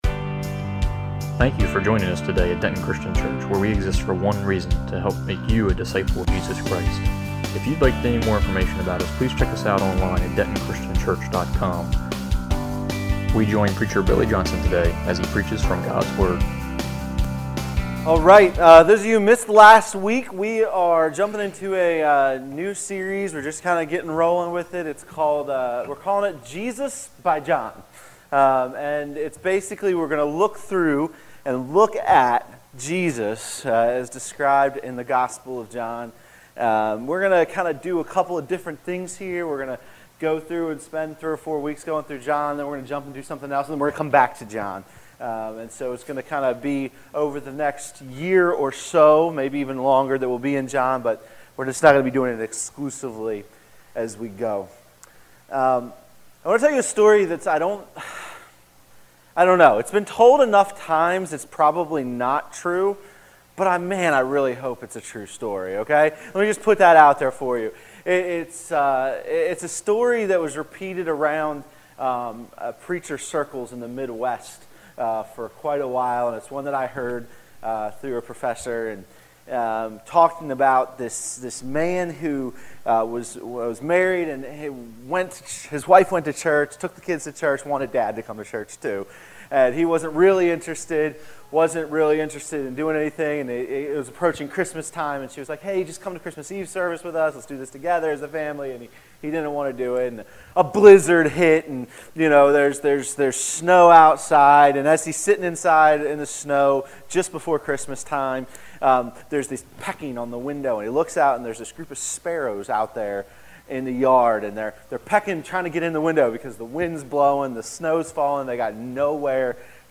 All Sermons , Jesus by John Book John Watch Listen Save Jesus became flesh to teach and save us.